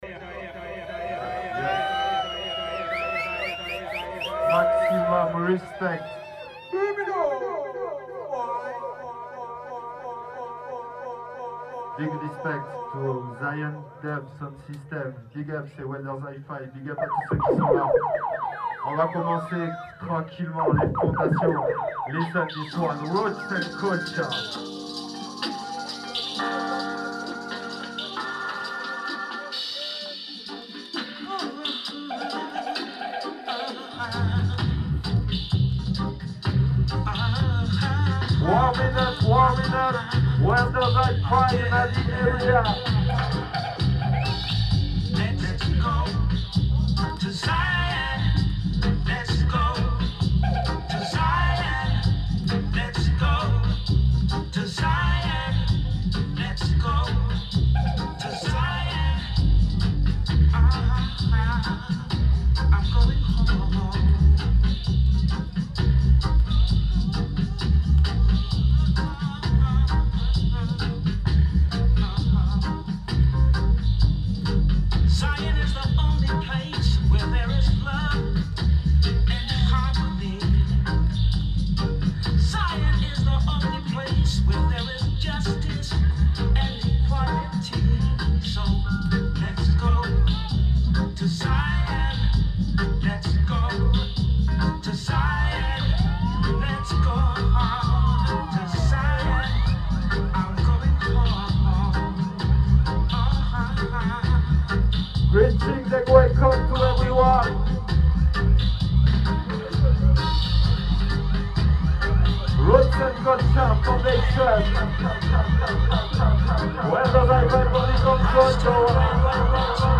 recorded at Clermont ferrand / Raymond Bar on 26/11/16